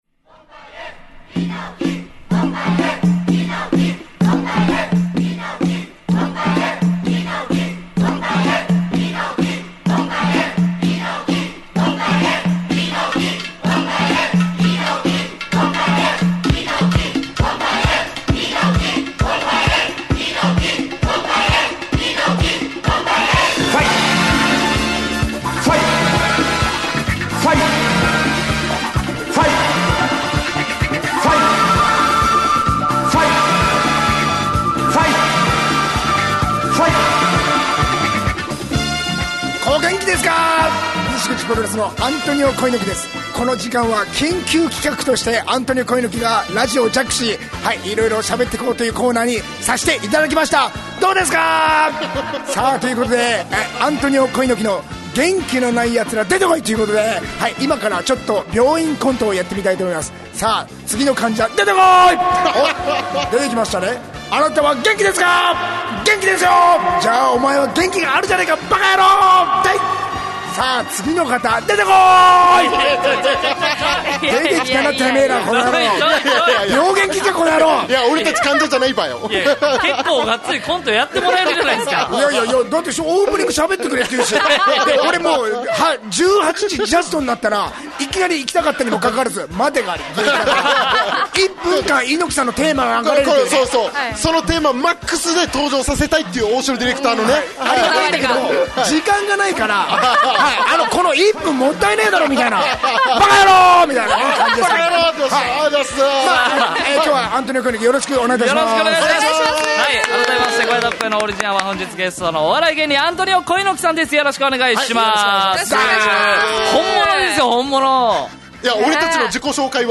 fm那覇がお届けする沖縄のお笑い集団・オリジンメンバー出演のバラエティ